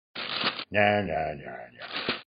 Talking Ben Saying Nananana